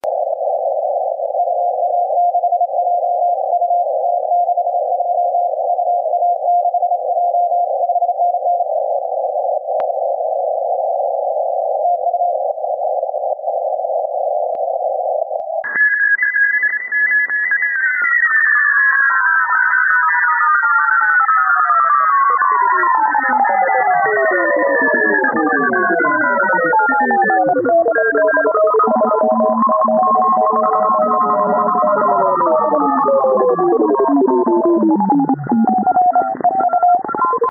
VK0EK 160m CW 2016-03-27 at 19z